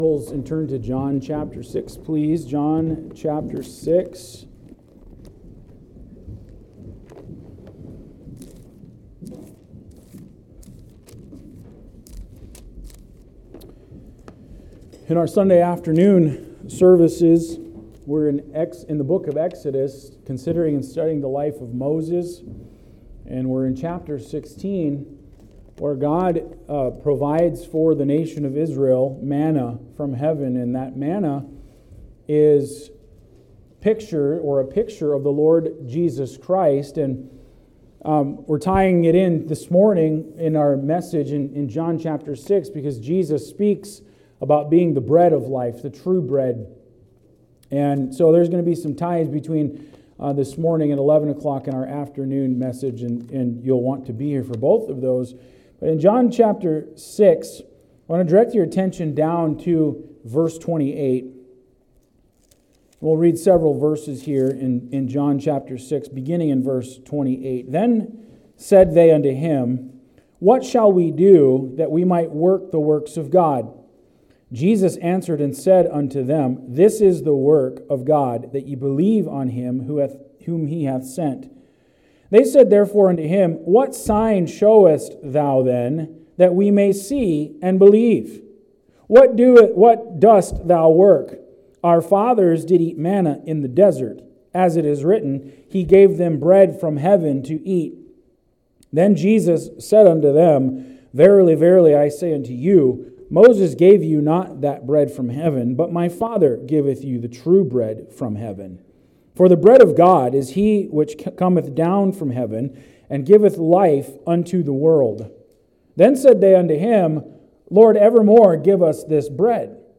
Sermons | Plack Road Baptist Church podcast لمنحك أفضل تجربة ممكنة ، يستخدم هذا الموقع ملفات تعريف الارتباط.